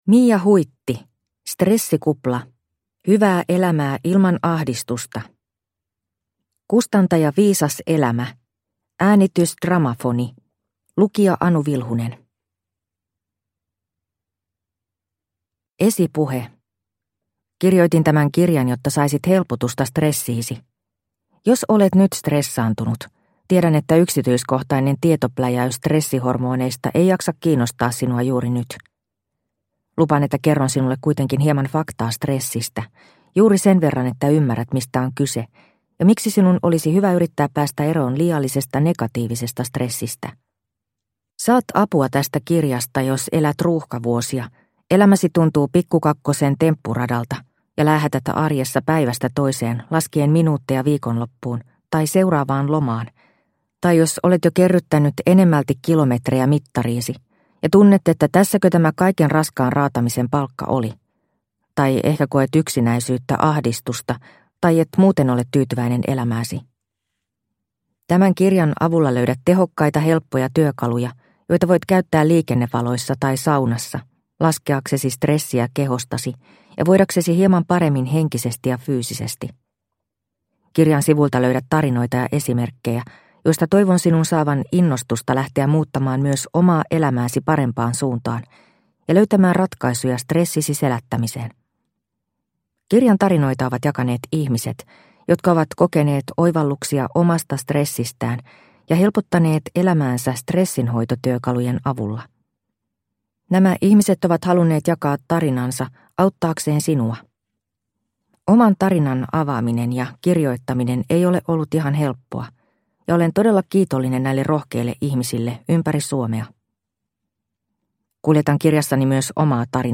Stressikupla – Ljudbok – Laddas ner